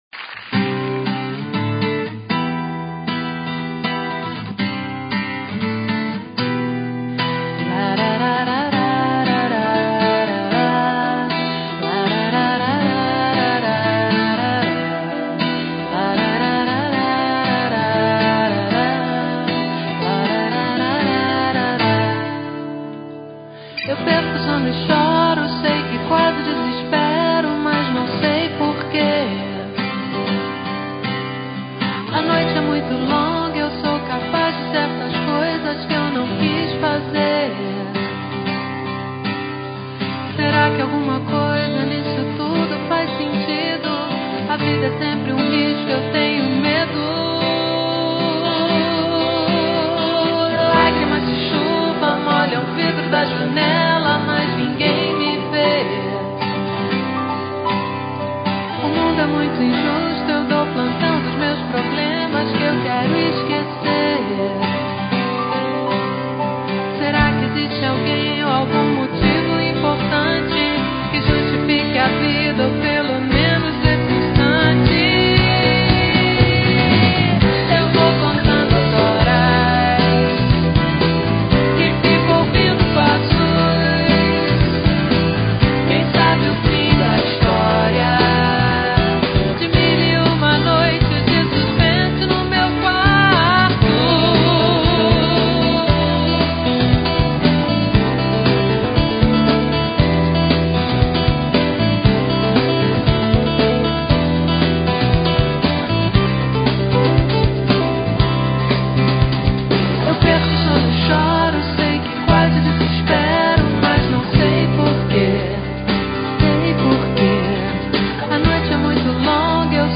lagrimas_chuva_acustico.mp3